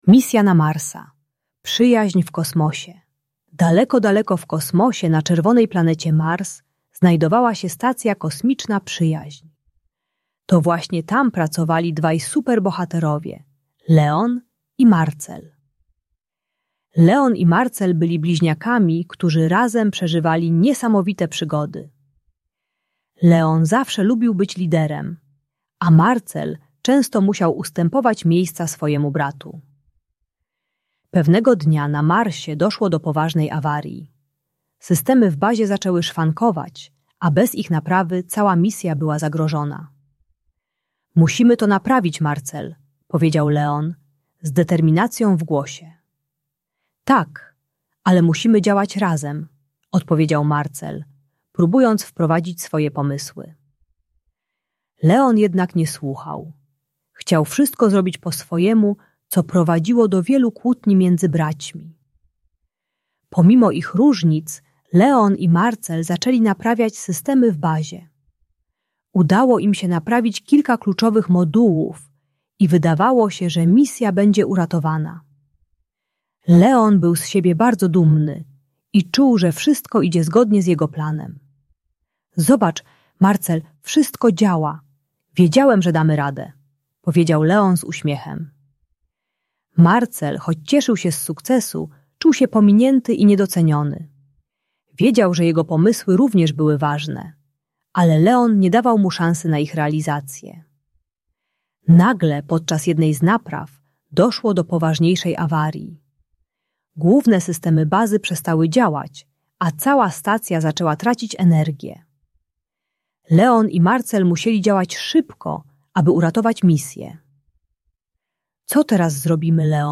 Przyjaźń w Kosmosie - Bunt i wybuchy złości | Audiobajka